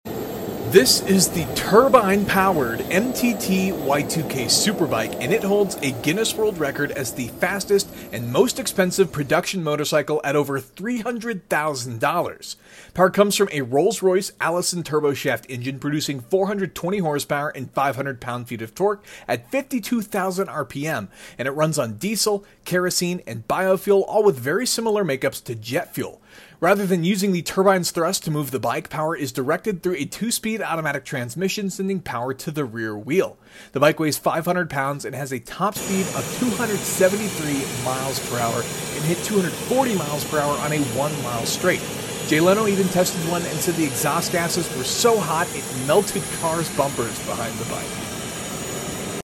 Jet Engine Bike! MTT Y2K Sound Effects Free Download